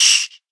Minecraft Version Minecraft Version snapshot Latest Release | Latest Snapshot snapshot / assets / minecraft / sounds / mob / silverfish / hit1.ogg Compare With Compare With Latest Release | Latest Snapshot